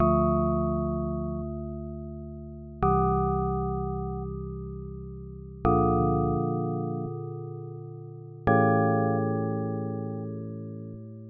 大提琴
描述：ld大提琴
Tag: 120 bpm Classical Loops Orchestral Loops 2.69 MB wav Key : Unknown